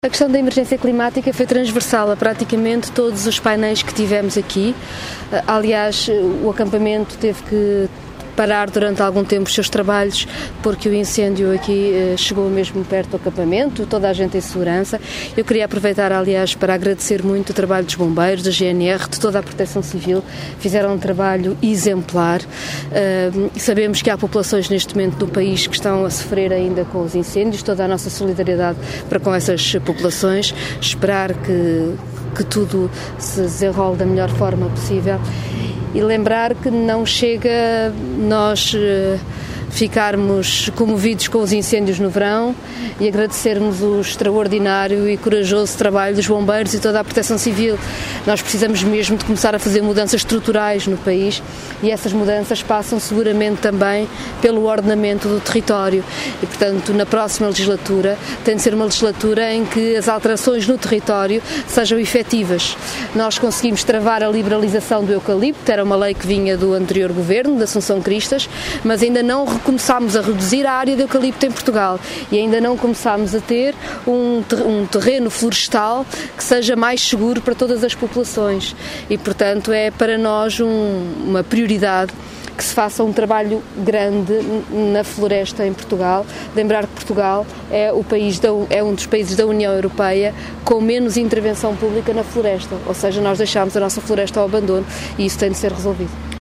Falando aos jornalistas à margem do plenário com os cerca de 200 jovens que participaram no acampamento, e que tiveram o incêndio que lavrou no sábado em Abrantes a poucos metros de distância, a dirigente partidária disse que “nas próximas legislaturas, tem de ser uma legislatura em que as alterações no território sejam efetivas”.